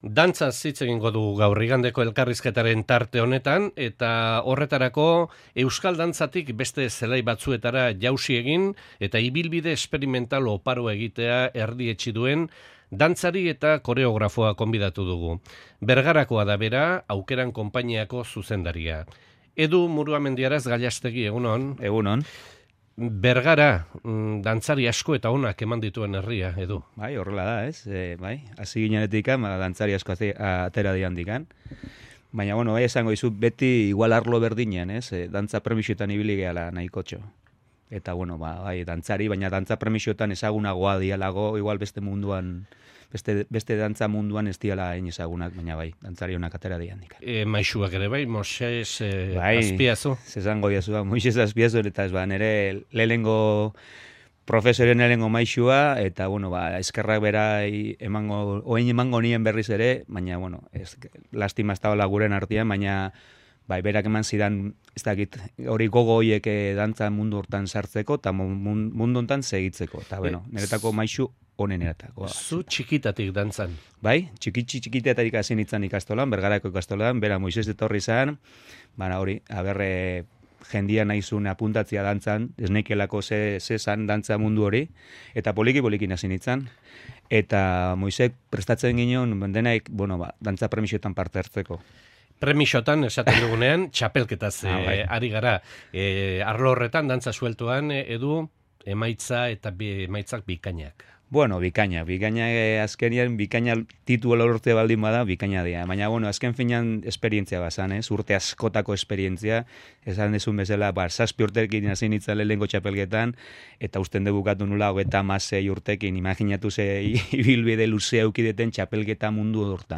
Konpainiari eustea lortuta, pozik azaldu da. Goiz Kronika.